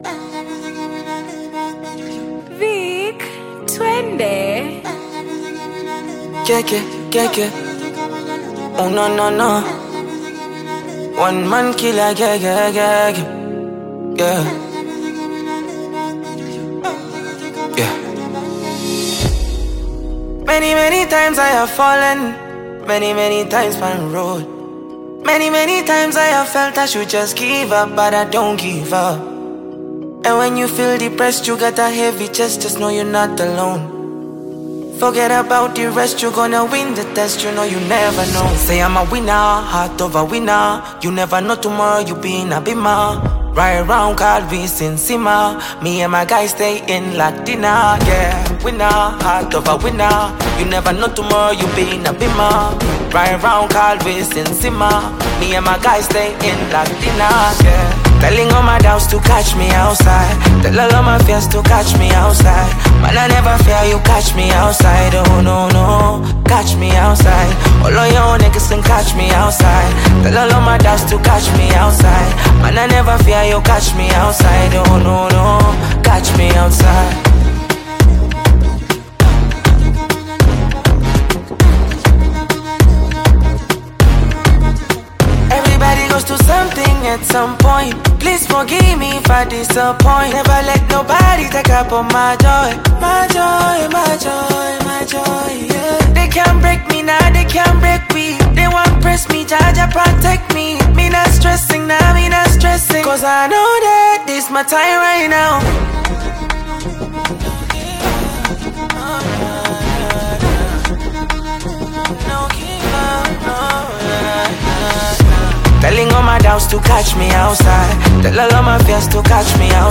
Kenyan talented singer